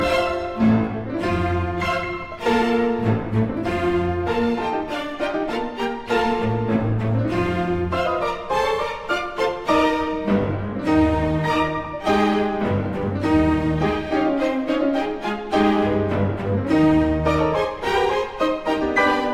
Version choisie pour les extraits : prestation de Anna Vinnitskaya et l'Orchestre National de Belgique sous la direction de Gilbert Varga lors de la finale du Concours Reine Elisabeth et avec lequel elle remporta le 1er Prix en 2007.
ceci est le schéma que l'on entend à travers les arpèges nerveux du piano et les pizzicati des cordes.